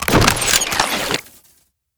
holster1.wav